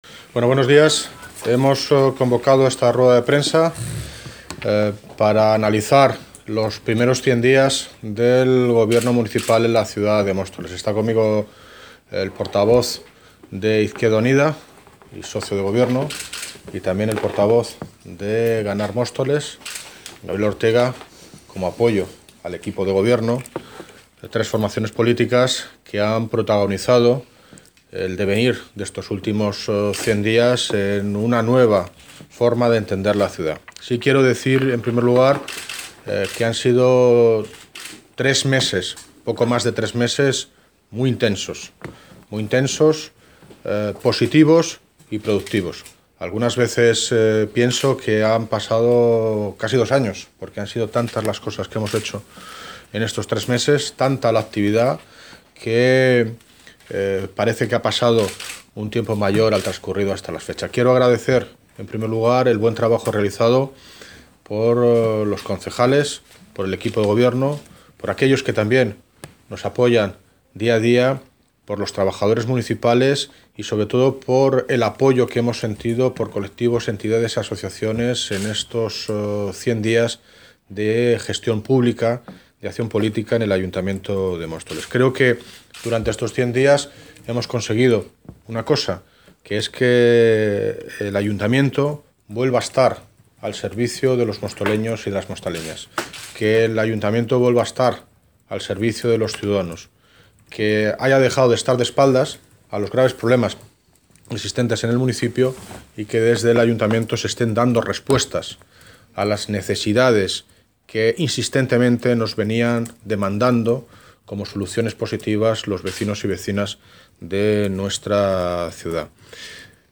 Audio - David Lucas (Alcalde de Móstoles) Balance 100 días gobierno